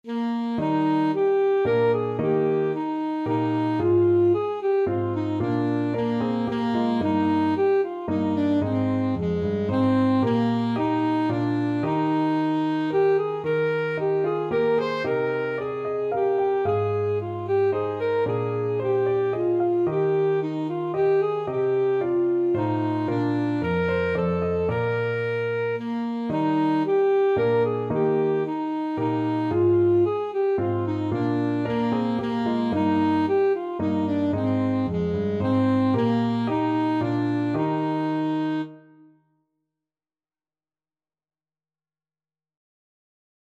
Alto Saxophone
Traditional Music of unknown author.
3/4 (View more 3/4 Music)
Moderately Fast ( = c. 112)
Ab4-C6
let_all_things_ASAX.mp3